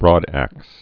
(brôdăks)